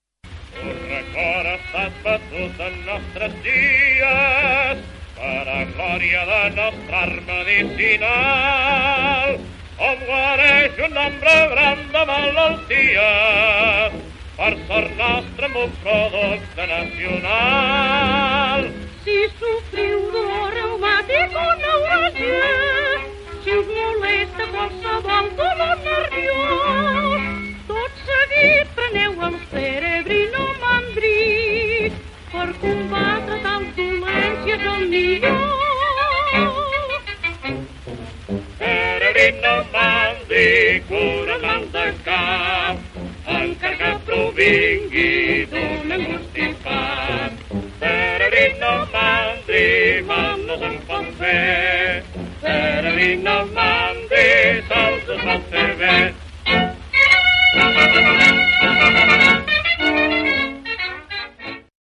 cantada a ritme de sardana